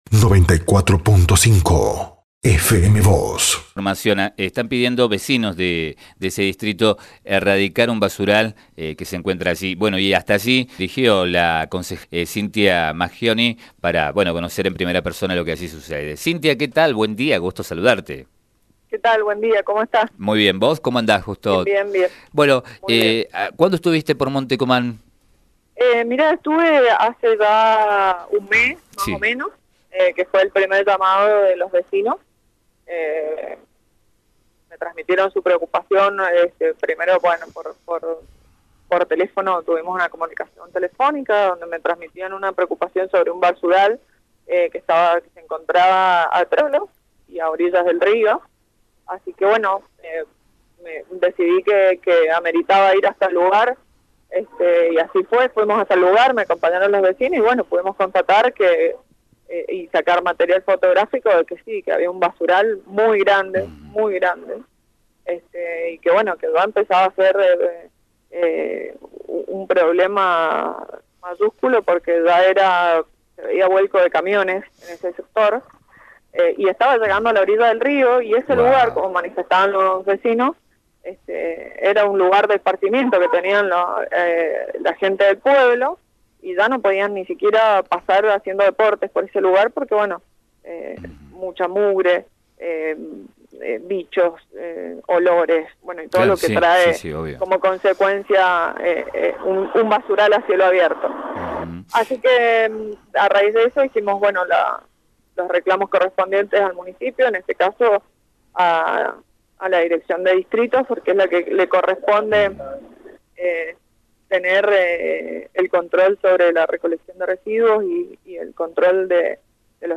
Vecinos de Monte Comán piden que se erradique un basural ubicado en ese distrito. Hasta allí se acercó la concejal por el radicalismo Cynthia Maggioni, a fin de conocer en profundidad la situación e intentar buscar una solución. Sobre ello dialogó con FM Vos (94.5) y con Diario San Rafael.